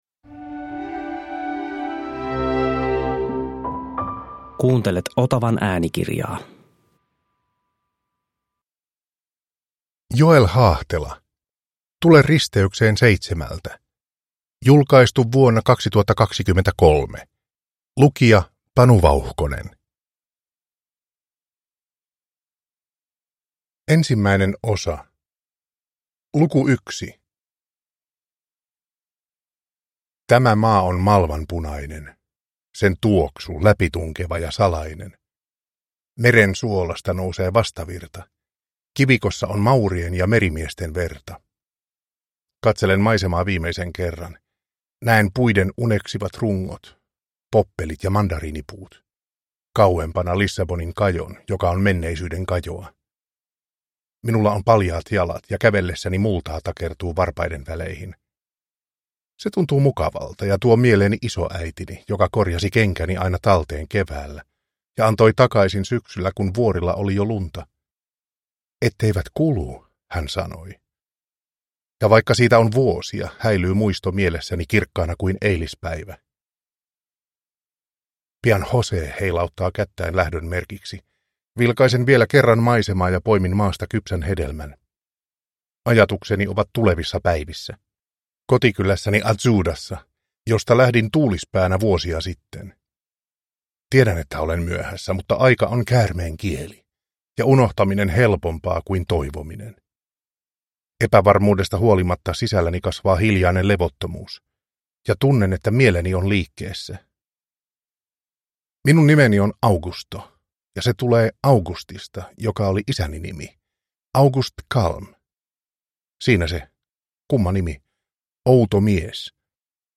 Tule risteykseen seitsemältä – Ljudbok – Laddas ner